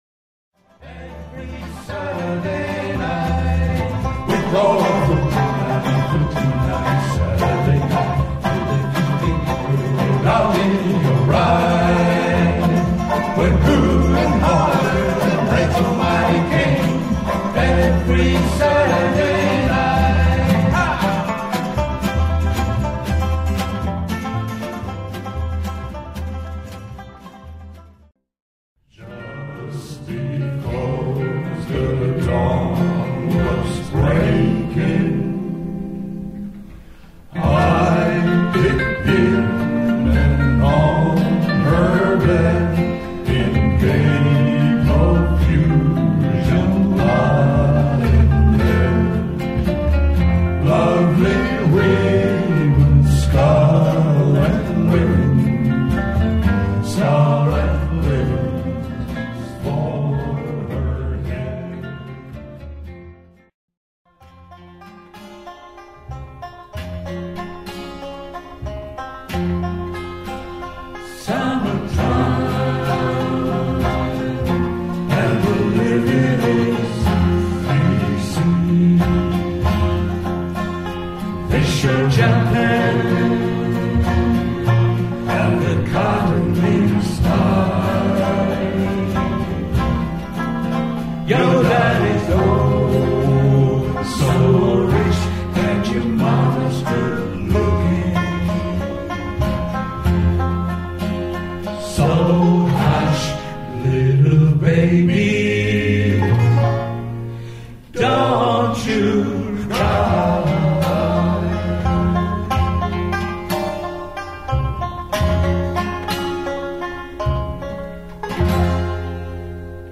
８月１１日（土）開催のブラフォー大会にいよいよ一週間とせまった５日（日）新所沢某所スタジオで、大会中心の最終練習を敢行しました。
１９回を迎えるブラフォー大会で「レバリーズ・フォー」が演奏する曲の練習録音は こちらから一部聴くことができます。